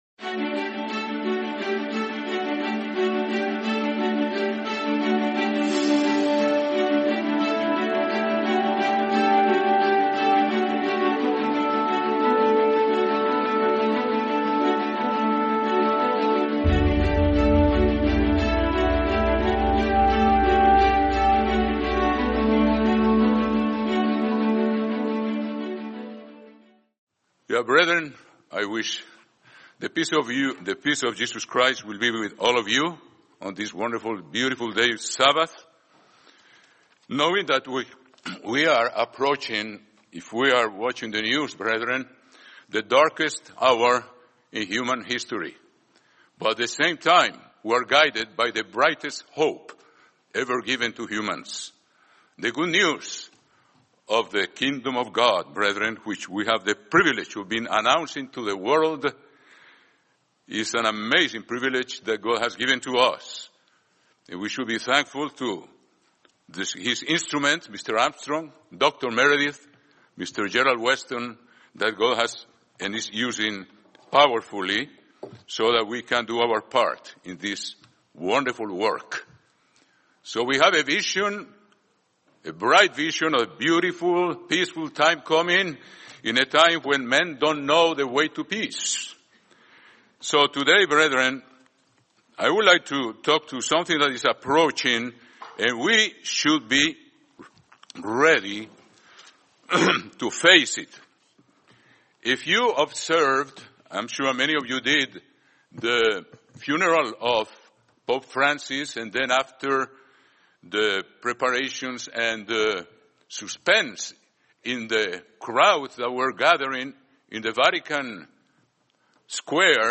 How to Tell If a Miracle Is From God | Sermon | LCG Members